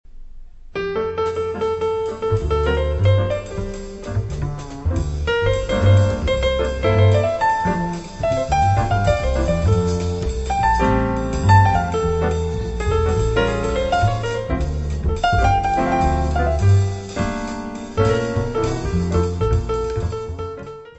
piano
baixo
bateria.
Music Category/Genre:  Jazz / Blues